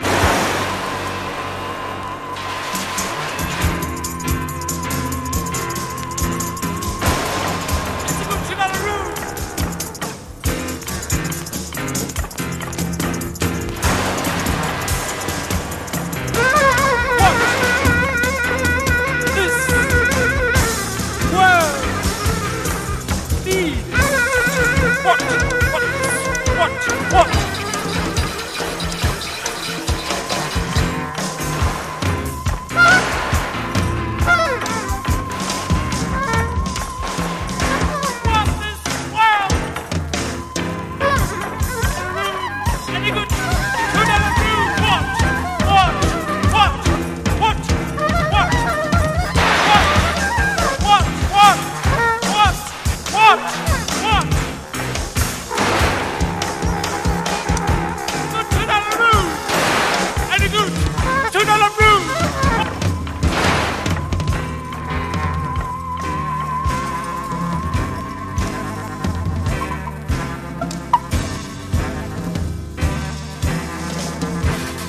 MEDIA:VG PVCアウターによるクモリ/ヤケあり 試聴でご確認ください